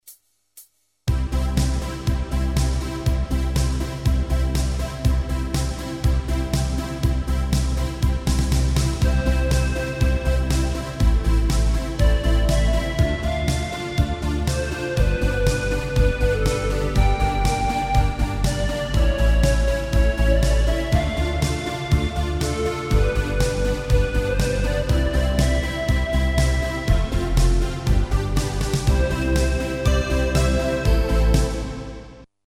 Tempo: 121 BPM.
MP3 with melody DEMO 30s (0.5 MB)zdarma